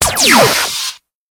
ray.ogg